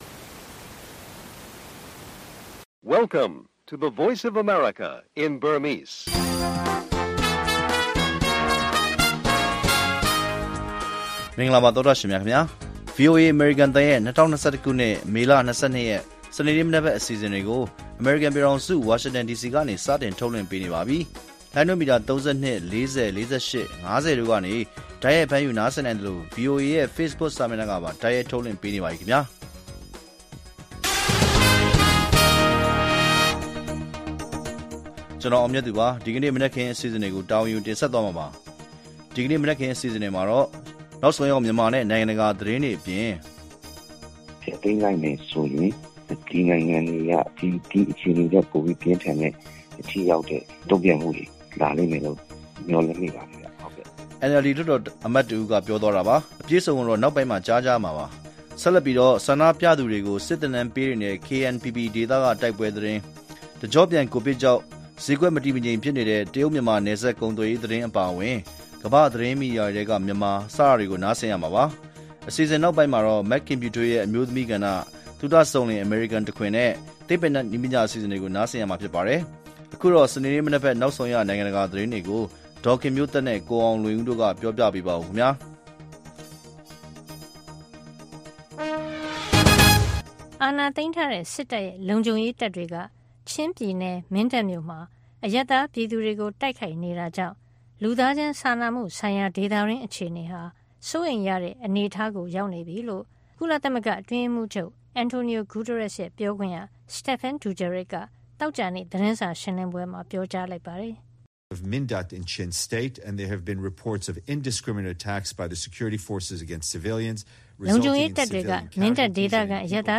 ဗွီအိုအေရဲ့ စနေနေ့ မနက်ပိုင်း ရေဒီယိုအစီအစဉ်ကို ရေဒီယိုကနေ ထုတ်လွှင့်ချိန်နဲ့ တပြိုင်နက်ထဲမှာပဲ Facebook ကနေလည်း တိုက်ရိုက် ထုတ်လွှင့်ပေးနေပါတယ်။